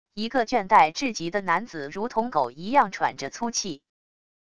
一个倦怠至极的男子如同狗一样喘着粗气wav音频